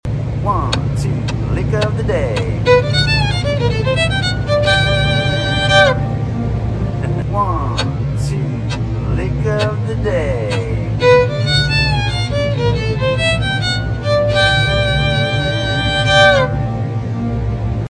🔥 Here’s a fiddle lick that demands attention—clean, punchy, and built to impress.